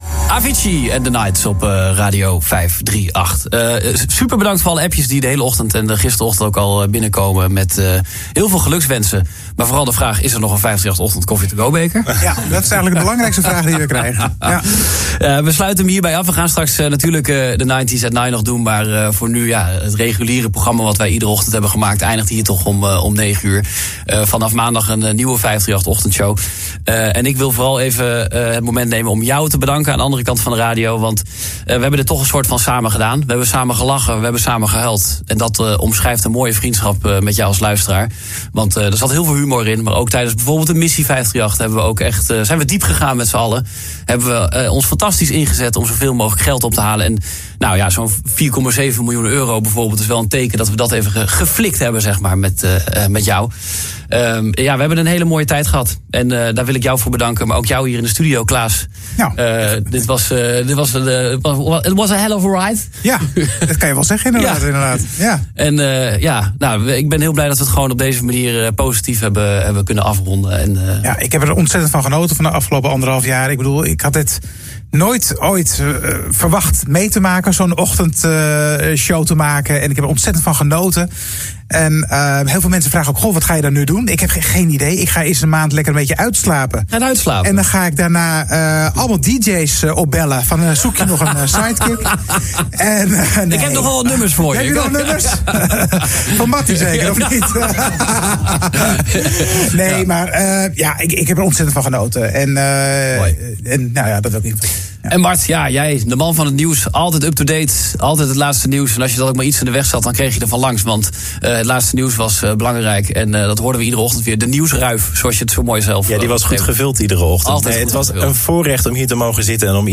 Het einde van de 538 Ochtend met Wietze en Klaas is hieronder te beluisteren.